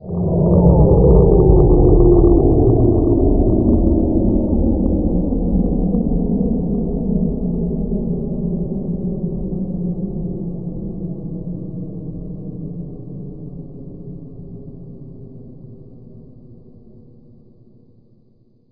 На этой странице собраны звуки из культовой хоррор-игры Five Nights at Freddy's. Слушайте онлайн или скачивайте бесплатно в mp3 самые узнаваемые аудиоэффекты: пугающие скримеры, жуткие голоса аниматроников, телефонные звонки Охранника и фоновые шумы пиццерии.